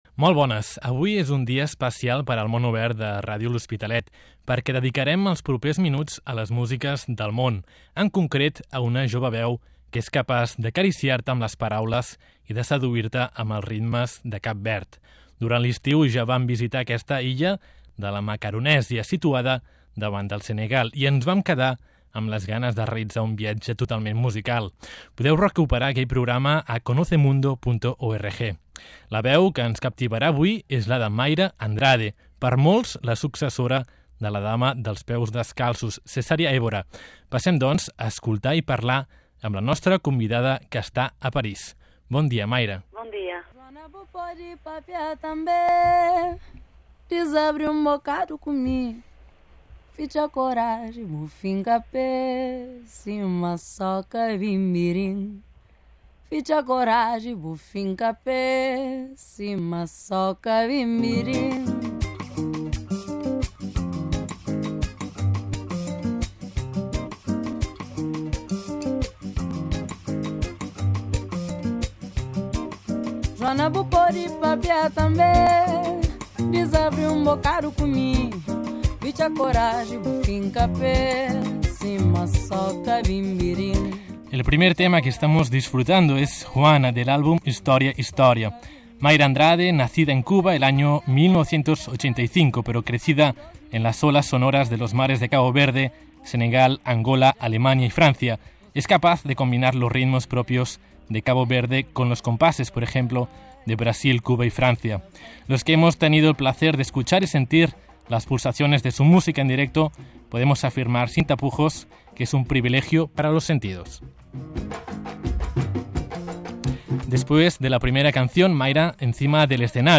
Mayra Andrade nos explica uno de los cuentos más populares de Cabo Verde: el cuento de Blimundo. Escucha la ENTREVISTA a Mayra Andrade